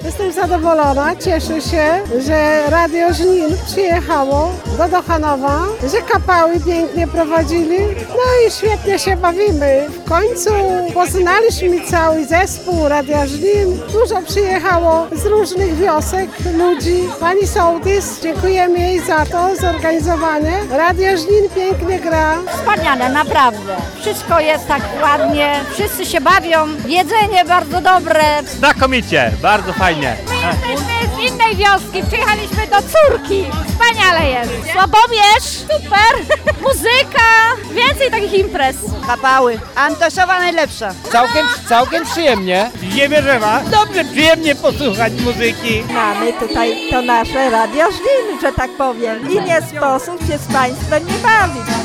Wczoraj ekipa Radia Żnin FM odwiedziła tę miejscowość, organizując "Biesiadę na Żywo".
Mieszkańcy, w rozmowie z naszą reporterką, nie kryli zadowolenia.